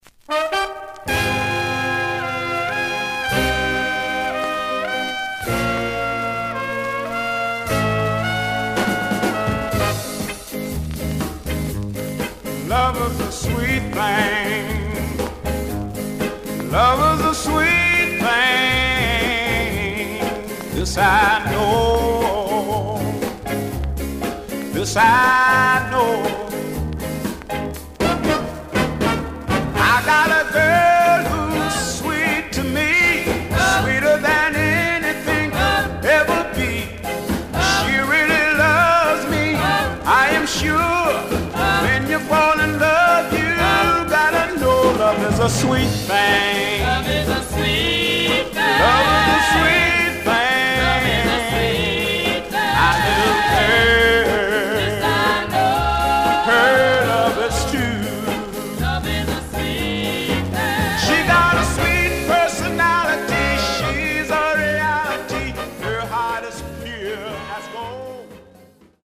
Condition Surface noise/wear Stereo/mono Mono
Soul